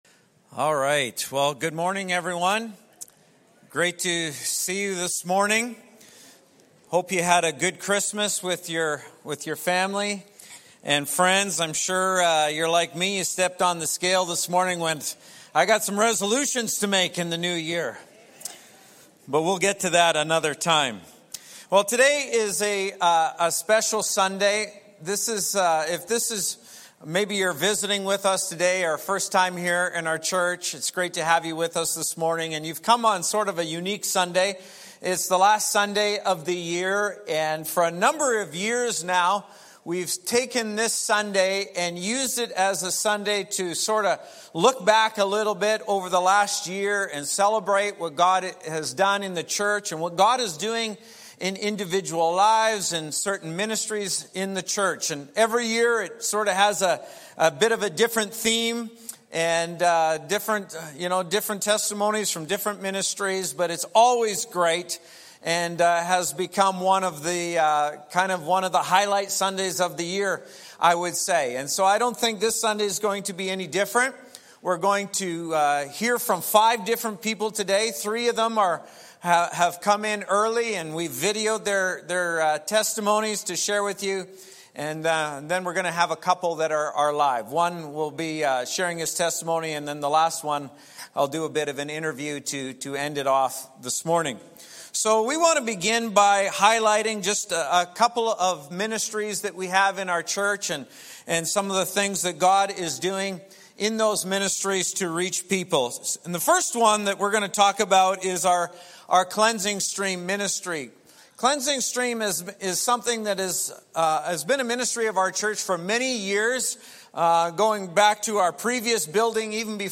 Thanks for joining us for Testimony Sunday! Our last Sunday service of 2024, we have a mix of pre-recorded and live testimonies, as we look back at the past year, we will hear some inspiring messages from members of our church, in the ways God has impacted their lives.